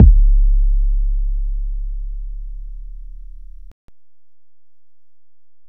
Waka KICK Edited (62).wav